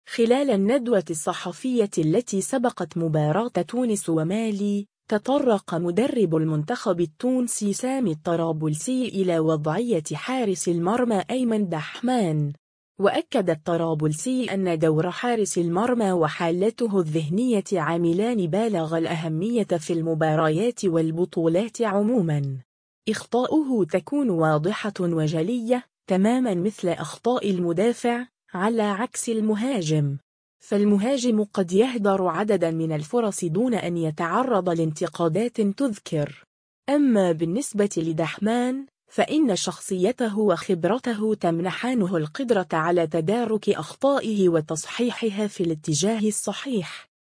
خلال الندوة الصحفية التي سبقت مباراة تونس ومالي، تطرّق مدرّب المنتخب التونسي سامي الطرابلسي إلى وضعية حارس المرمى أيمن دحمان.